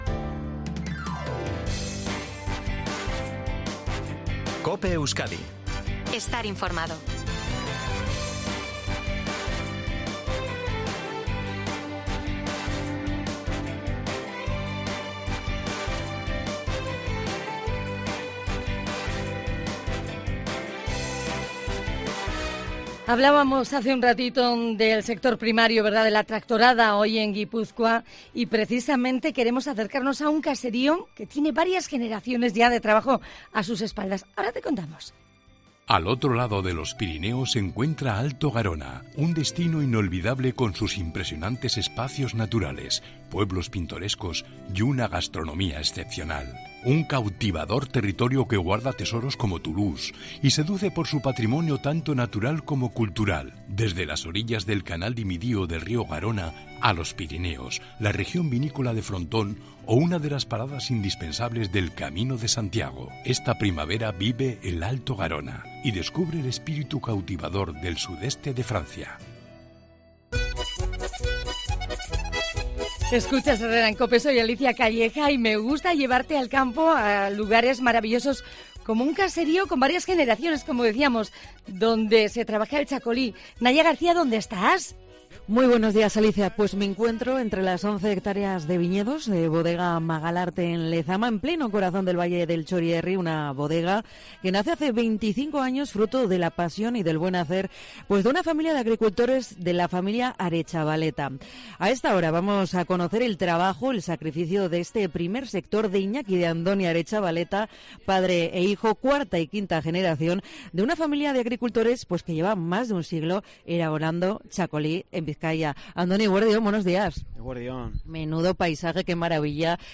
En COPE recorremos las once hectáreas de viñedos del caserío Magalarte de Lezama para conocer el día a día de esta histórica familia de agricultores, que lleva 130 produciendo vino